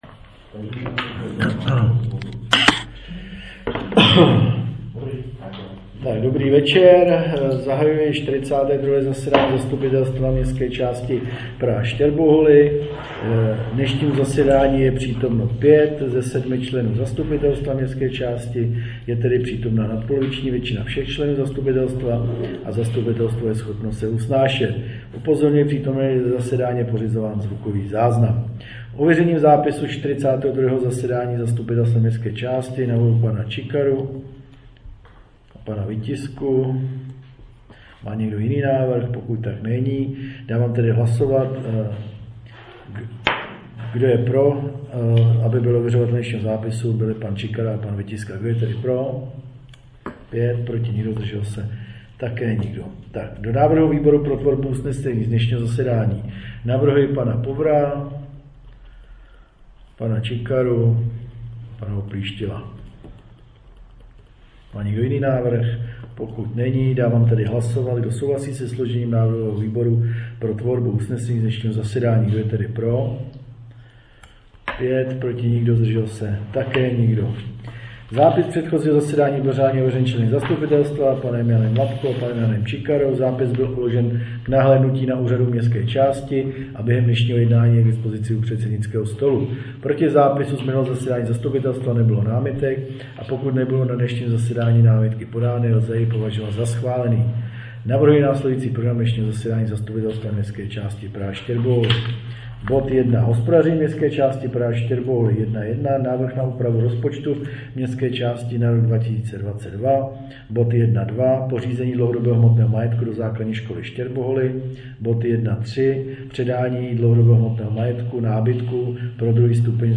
Zvukový záznnam z 42. zasedání zastupitelstva MČ Praha Štěrboholy